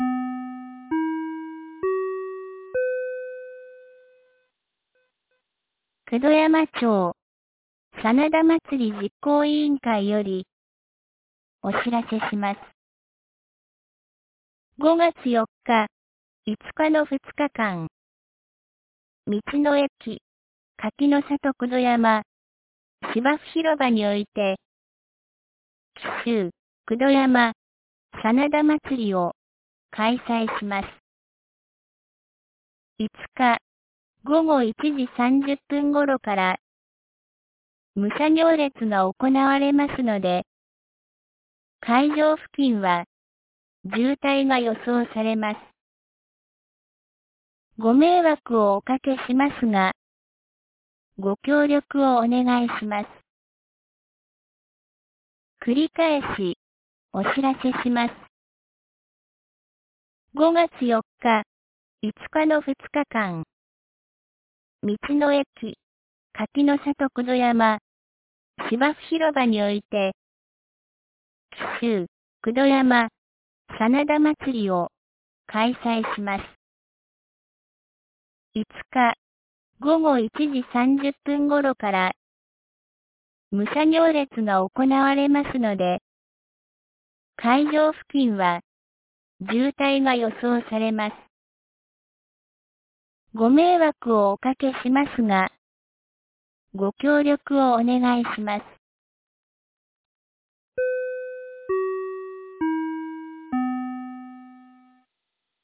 2025年05月18日 09時01分に、九度山町より全地区へ放送がありました。